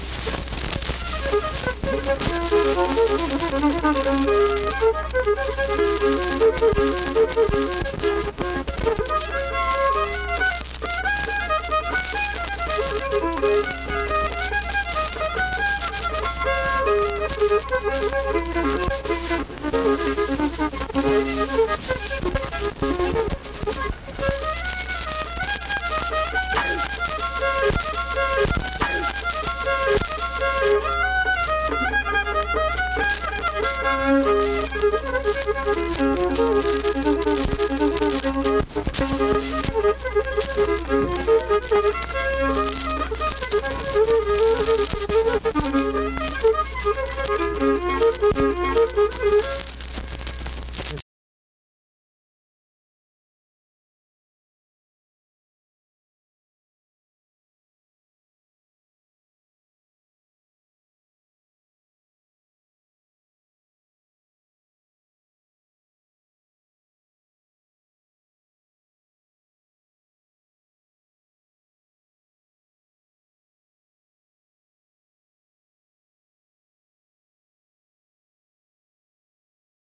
Instrumental fiddle performance.
Instrumental Music
Fiddle
Vienna (W. Va.), Wood County (W. Va.)